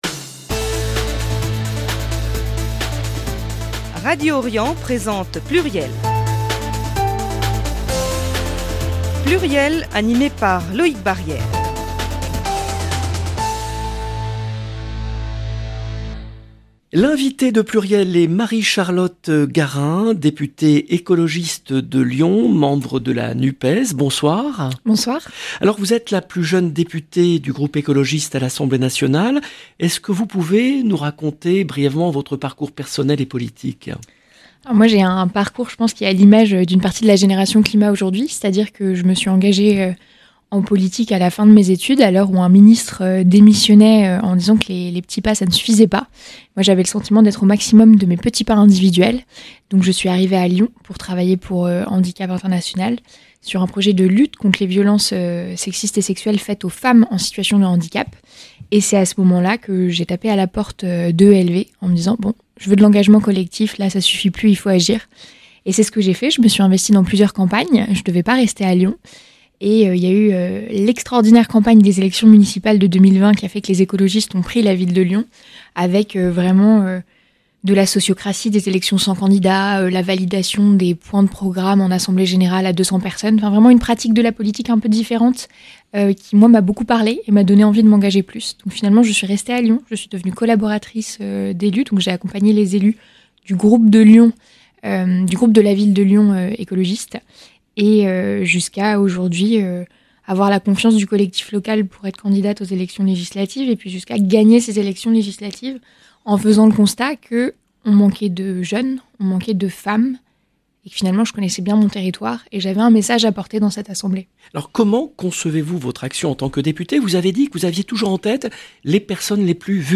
Marie-Charlotte Garin, députée écologiste de Lyon